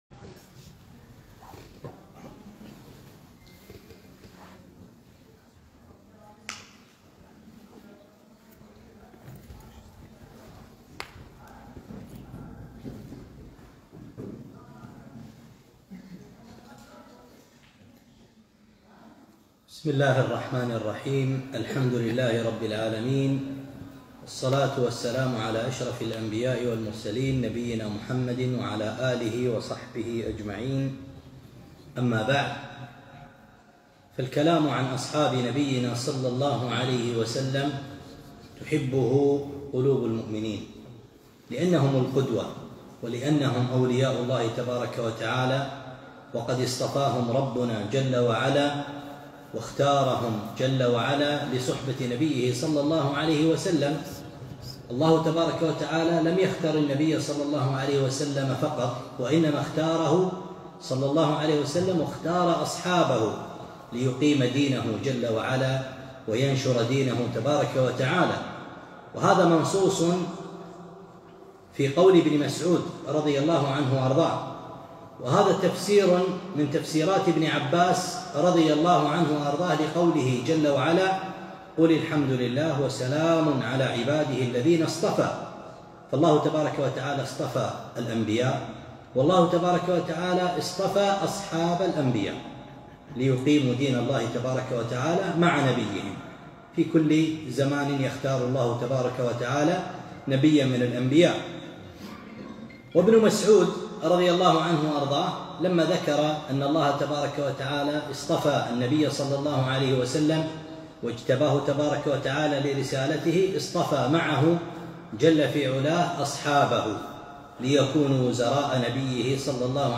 محاضرة - سيرة أبي أيوب الأنصاري (رضي الله عنه)